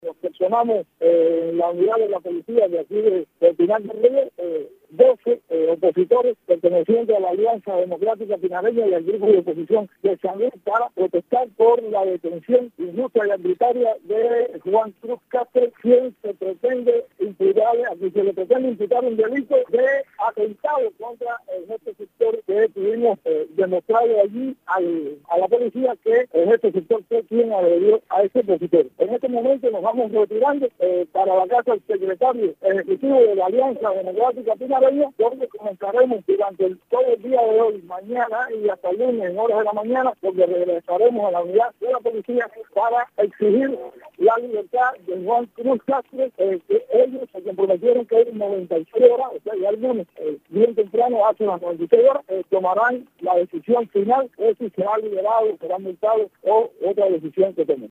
Directamente desde las cercanías de la unidad